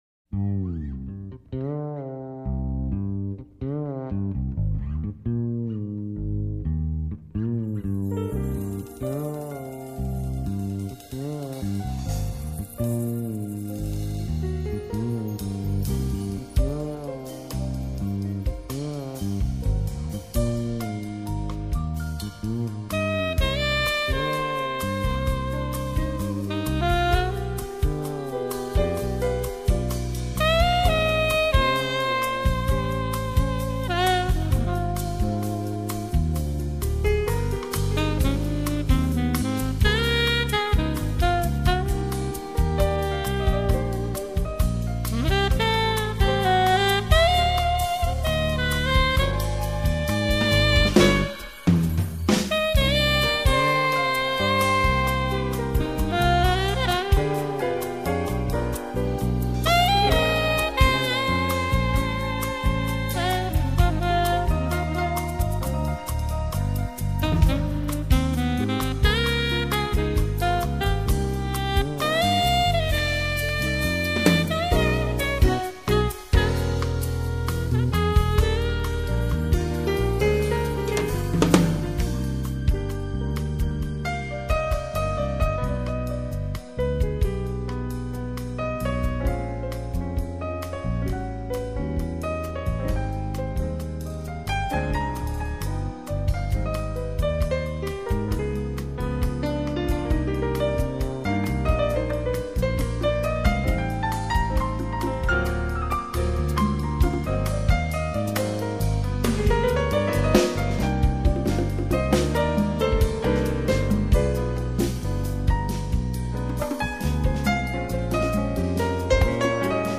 quintet